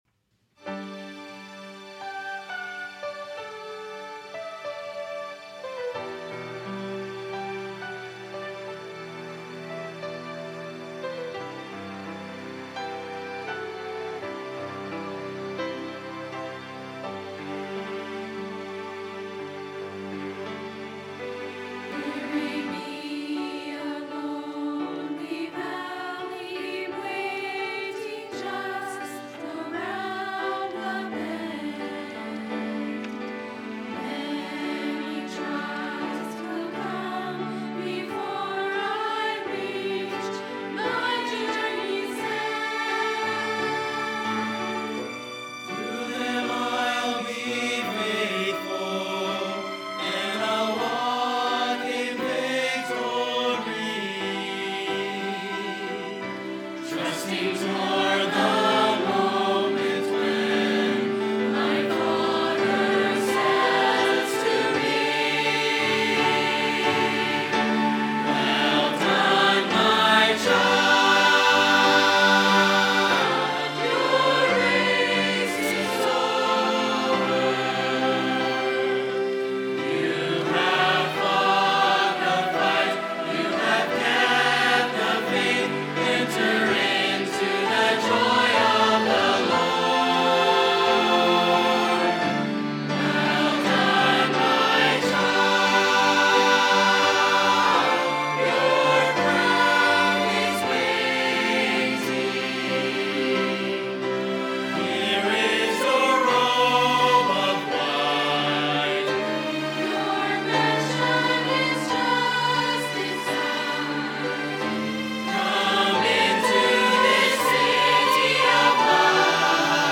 by VBC Choir & Orchestra | Verity Baptist Church
Choir-3_1.mp3